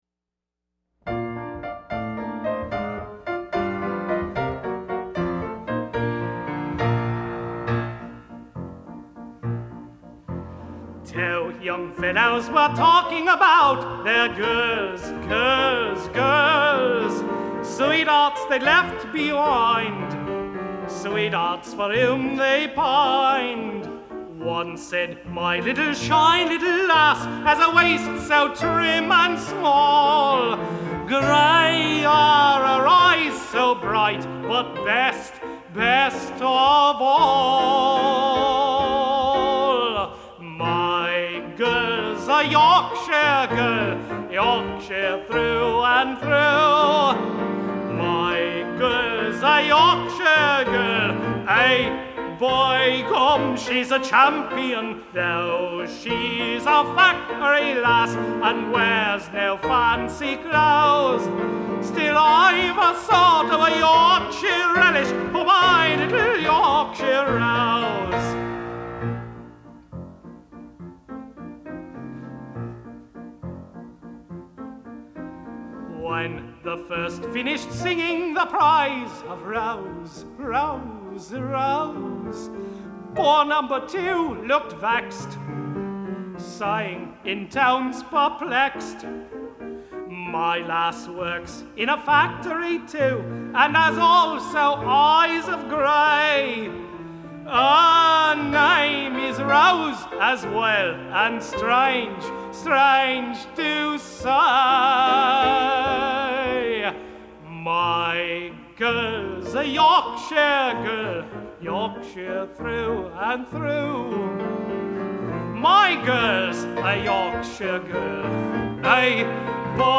My Girl’s A Yorkshire Girl, a song in three-quarter time with three verses about three men who share a single woman.